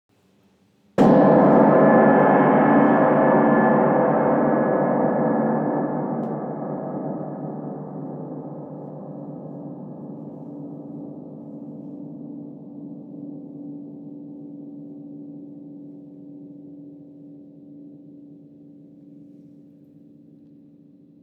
grand_1coup_moy.wav